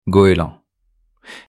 Goeland.mp3